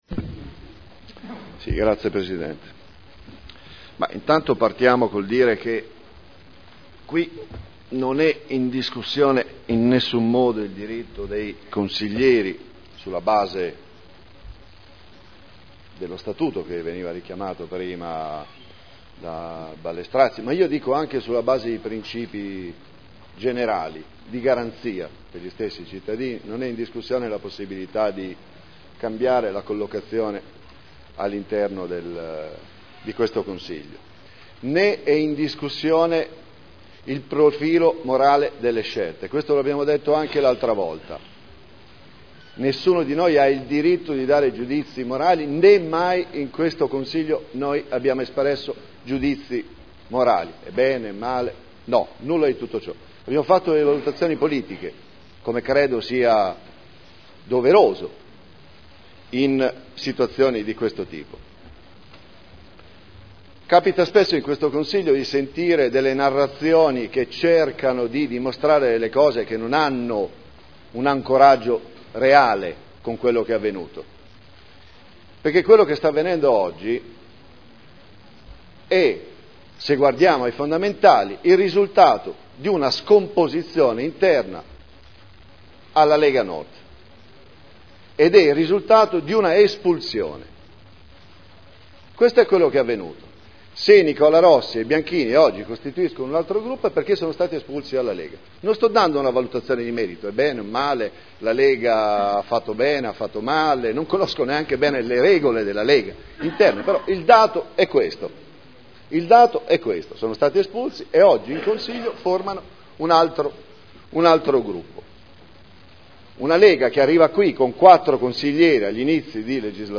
Seduta del 12 dicembre Commissione consiliare permanente Programmazione e Assetto del Territorio, Sviluppo economico e Tutela ambientale – Modifica Dibattito